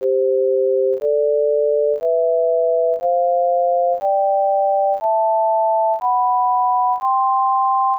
Here is an example of a trivial additive synthesis. introscale.sco plays a simple major scale. introscales.orc defines the "instrument" on which the scale is played by summing 2 sine waves, and modulating the amplitude with a piecewise-linear envelope.